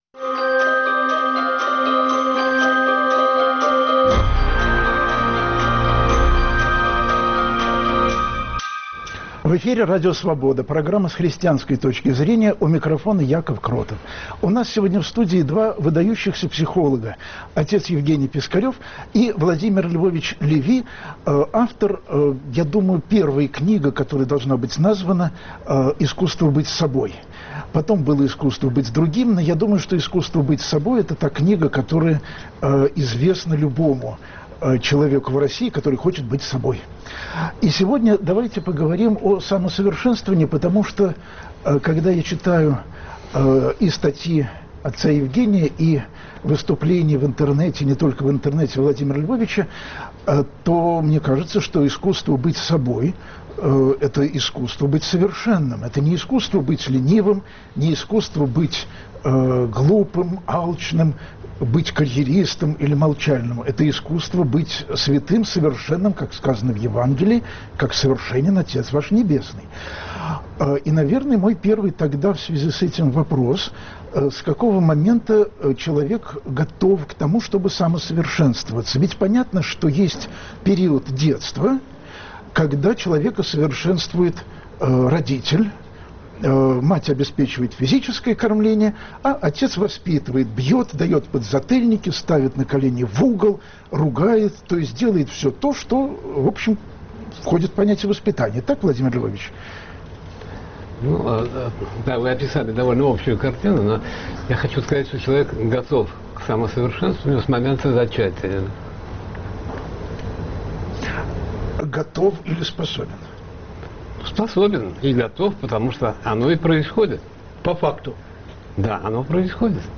Нужен ли Бог для развития человека? У нас в студии два выдающихся психолога